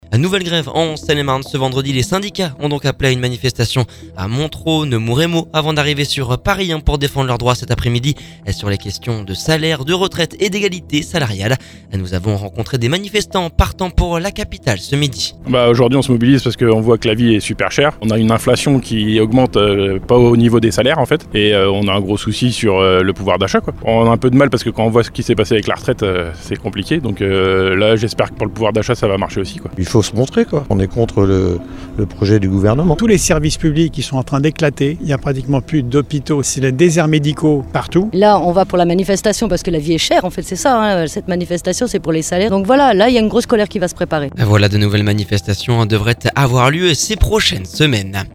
Nous avons rencontré des manifestants partants pour la capitale ce midi…